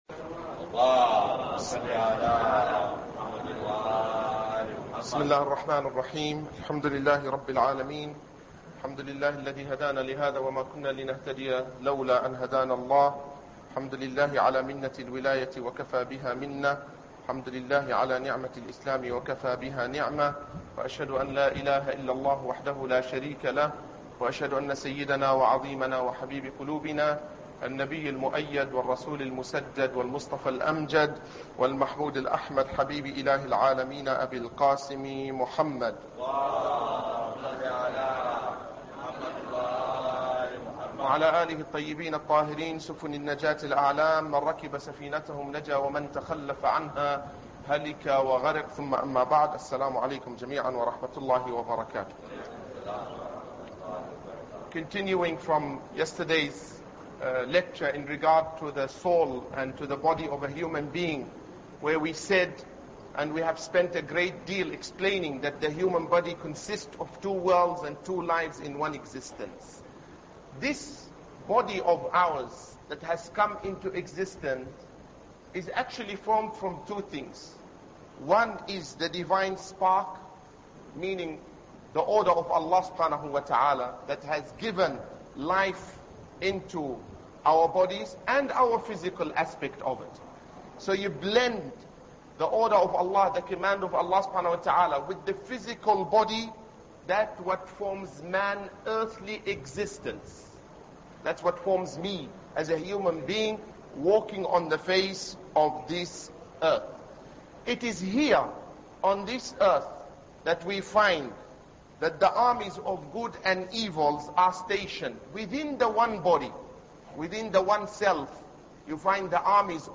Ramadan Lecture 3